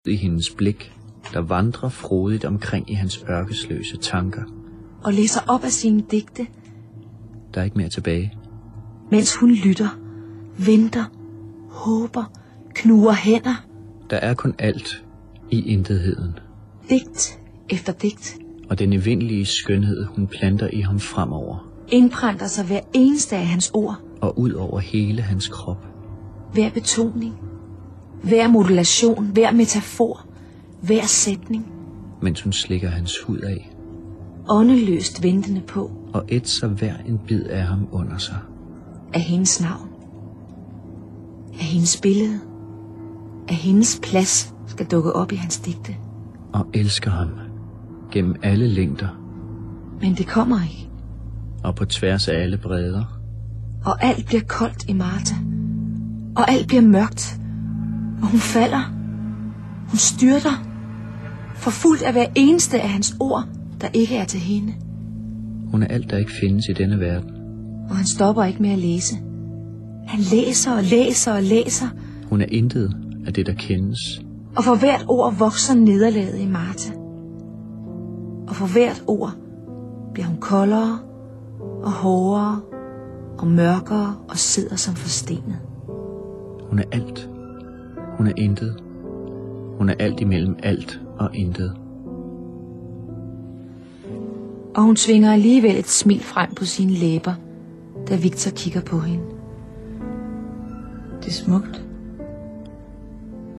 The uvular fricative throws me off.
It *is* exceptionally clearly articulated – just what you’d expect from a radio drama. It’s about a man reading his poems to a woman and she starts to feel more and more heartbroken since none of the poems are about her.